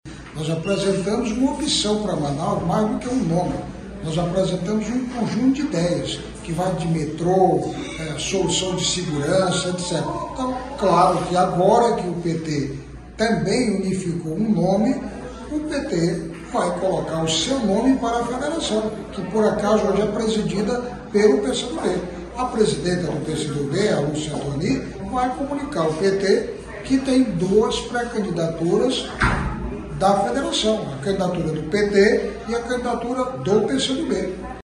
Em declaração a BandNews Difusora FM, o pré-candidato a prefeito Eron Bezerra, esclareceu que, com a decisão, o grupo político passa a ter dois pré-candidatos.